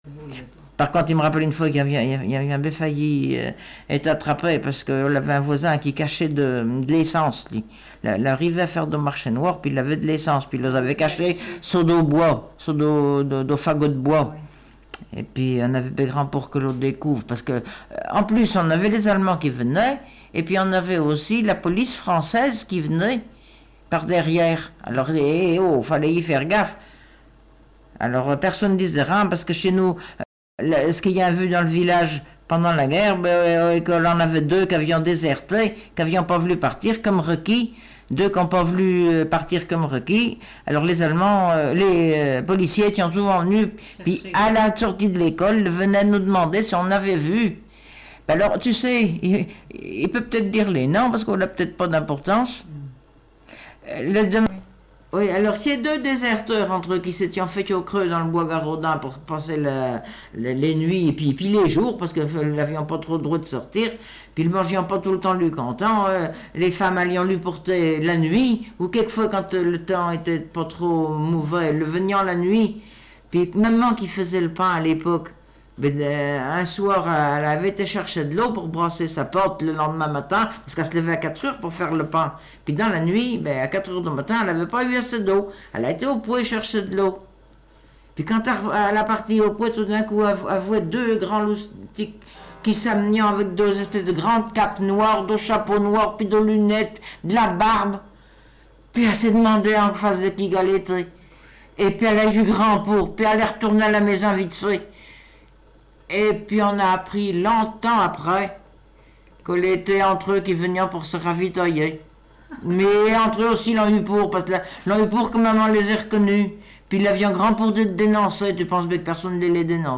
Langue Patois local
Catégorie Témoignage